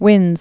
(wĭnz)